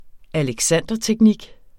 Udtale [ aləgˈsanˀdʌtεgˌnig ]